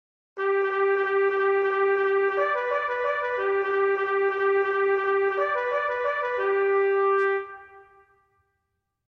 Traditional : Charge - a military bugle call: Sheet Music
Traditional Bugle Call : Charge